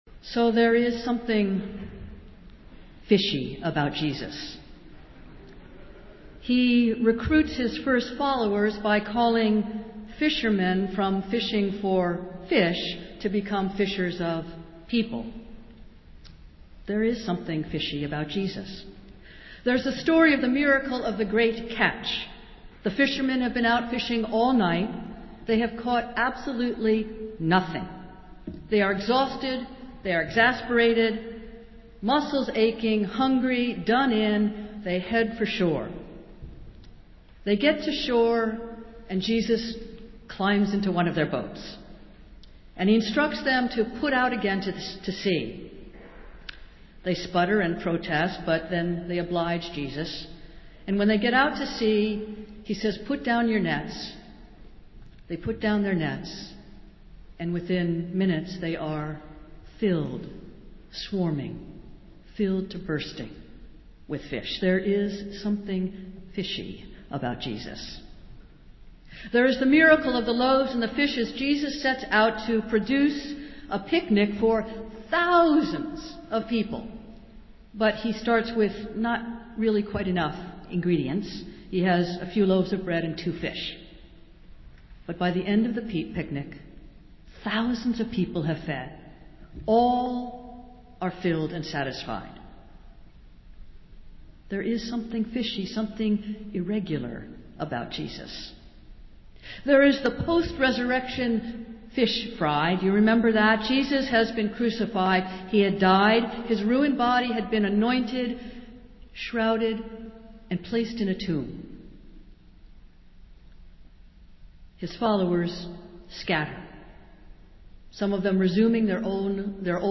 Festival Worship - Third Sunday after Epiphany